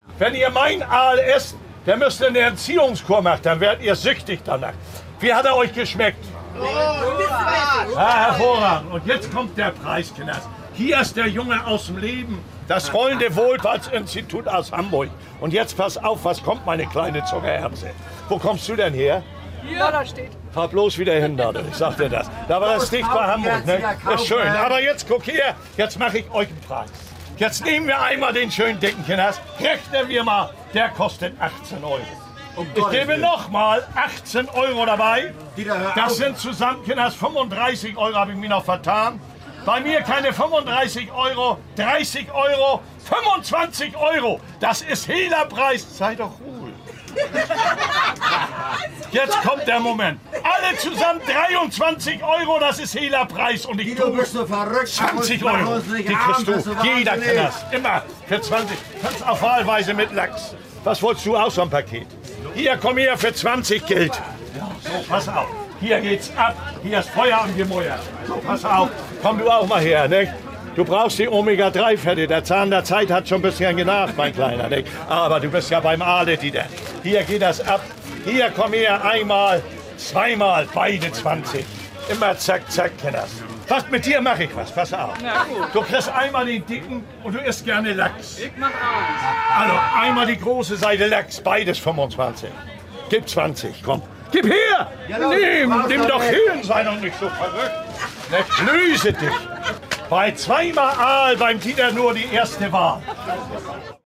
Aale-Dieter in Aktion auf dem Hamburger Fischmarkt.